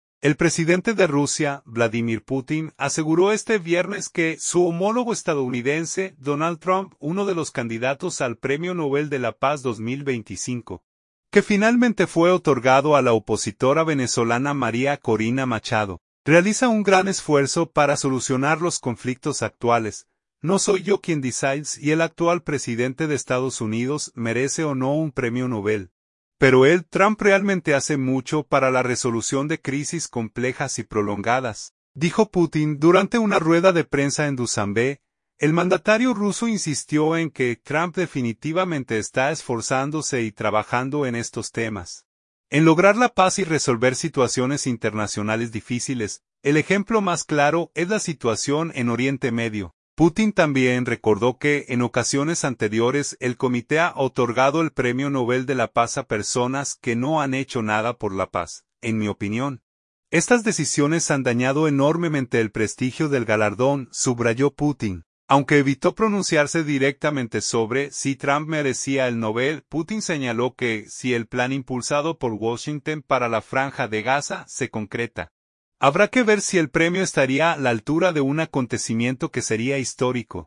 “No soy yo quien decide si el actual presidente de Estados Unidos merece o no un premio Nobel, pero él (Trump) realmente hace mucho para la resolución de crisis complejas y prolongadas”, dijo Putin durante una rueda de prensa en Dusambé.